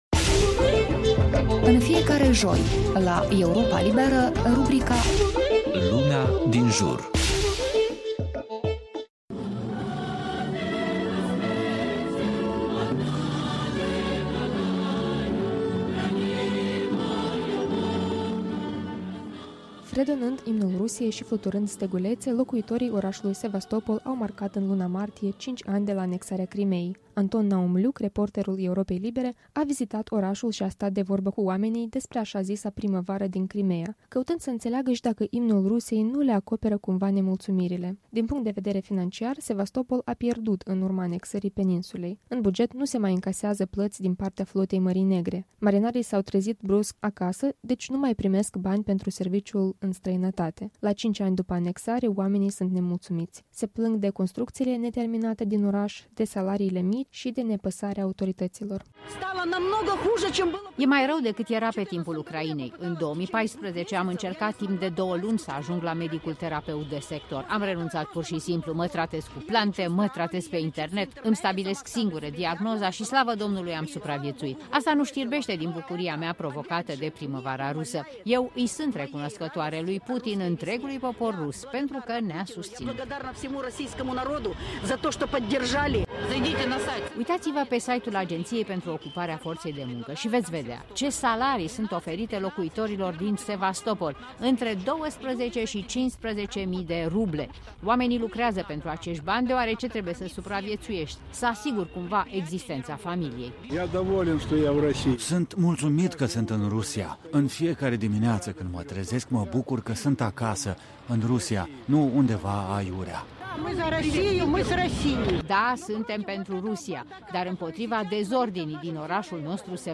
Fredonând imnul Rusiei și fluturând stegulețe, locuitorii orașului Sevastopol au marcat în luna martie cinci ani de la anexarea Crimeii.